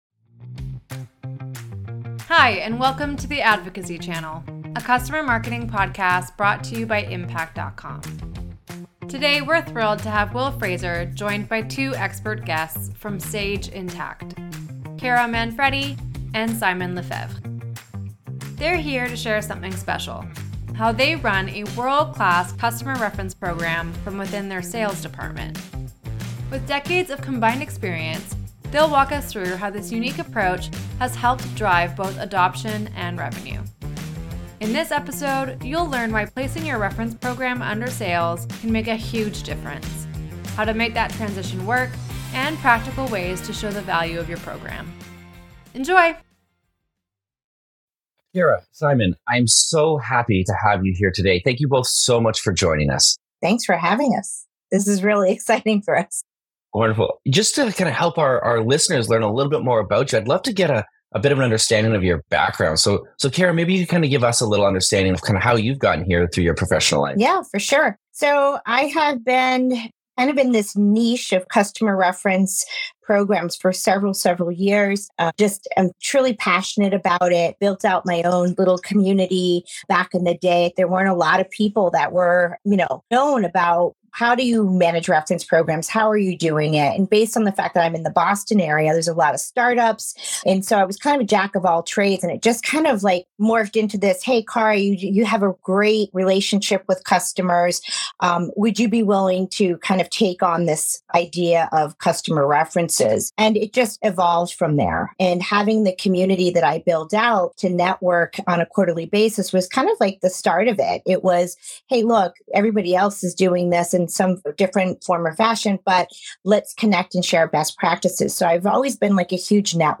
Struggling to convert visitors into prospects and clients? In this LIVE "Advice Line" episode